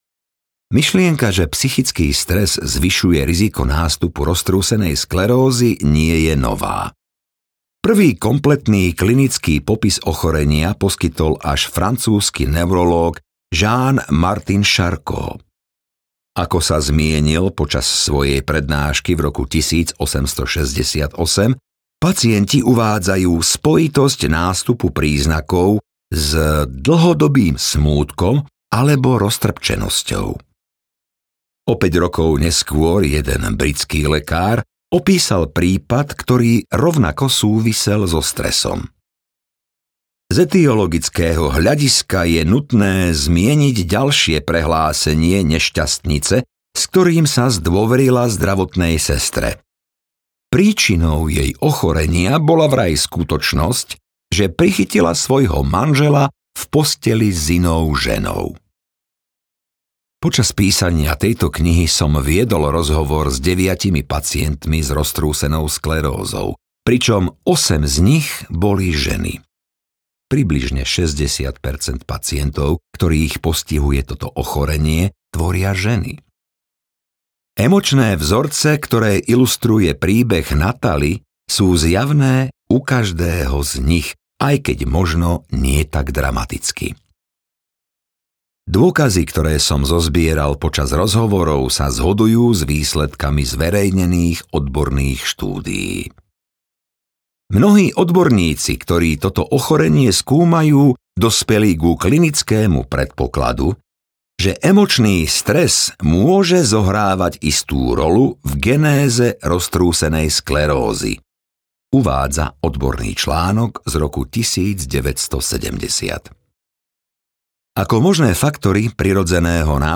Keď telo povie nie audiokniha
Ukázka z knihy